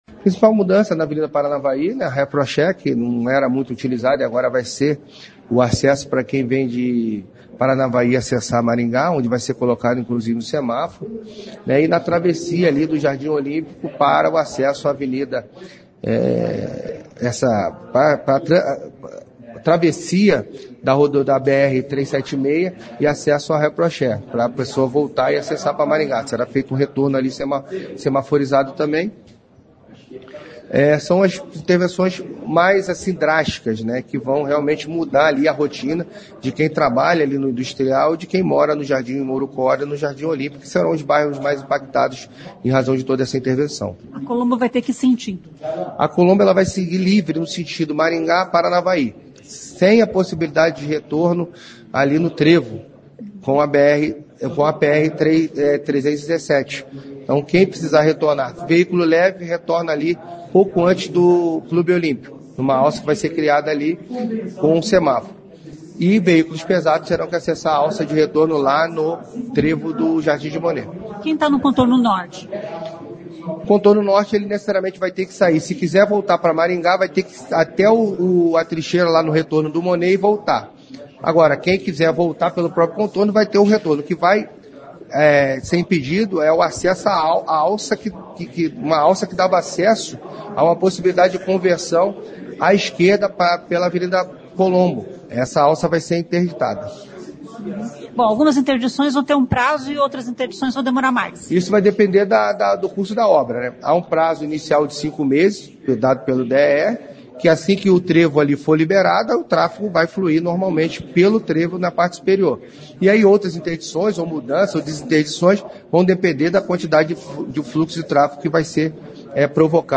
O secretário de Mobilidade Urbana, Luiz Alves, destaca as principais mudanças: